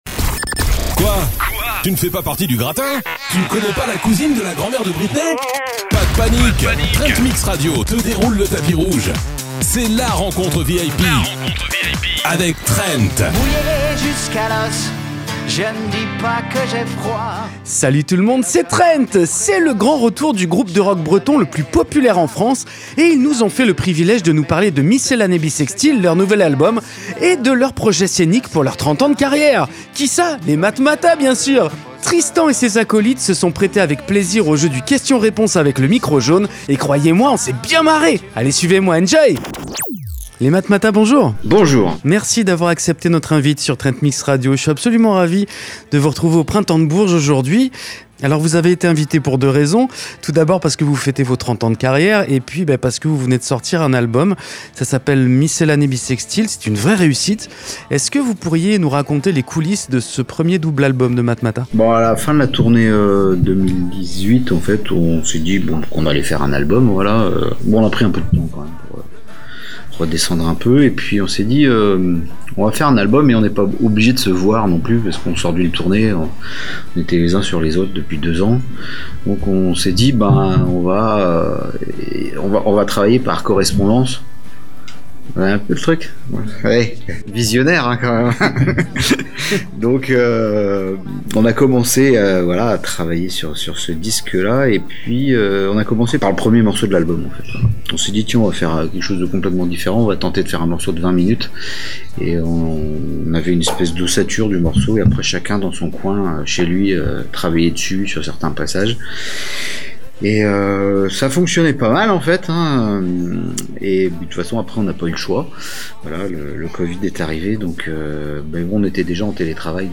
Matmatah : l'interview !
Tristan et ses acolytes se sont prêtés avec plaisir au jeu du question-réponse avec le micro jaune, et croyez-moi, on s'est bien marrés !